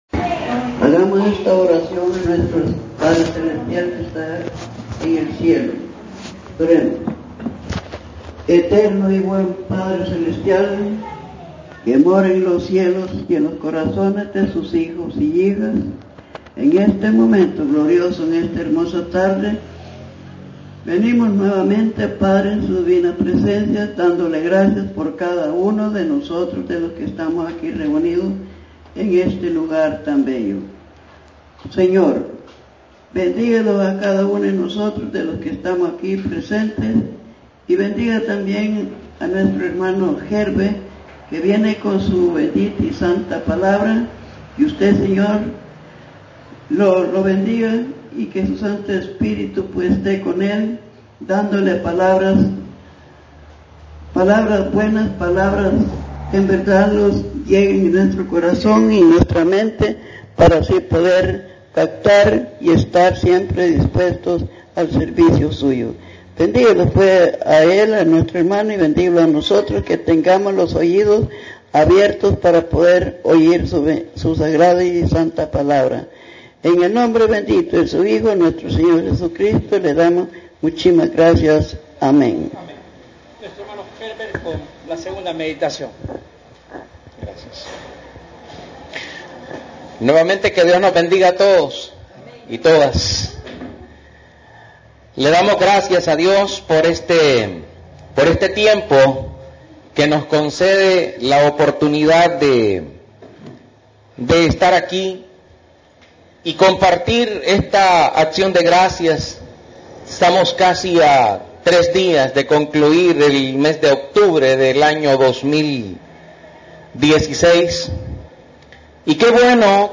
Tengamos gratitud (culto de acción de gracias)